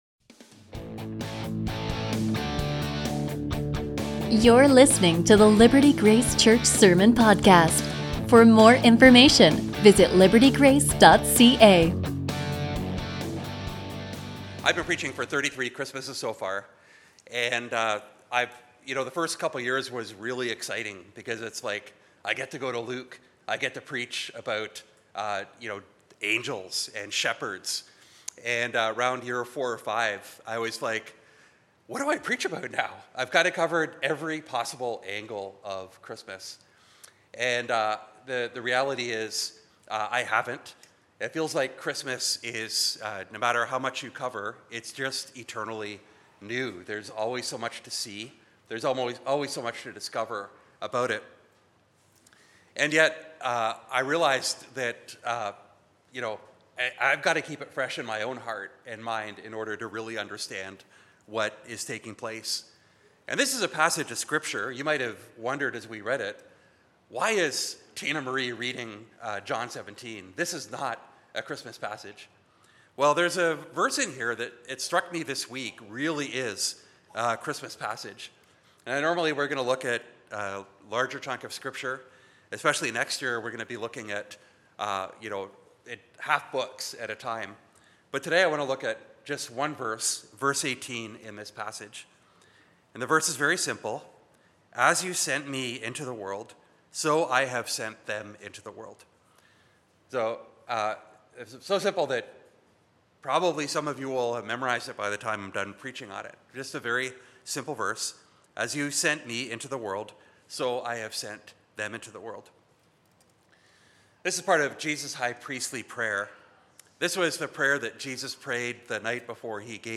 A sermon from John 17:18